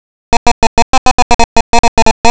Toledo -- Frases interrogativas sin que -- expansión de objeto (S+V+O+EXP_O)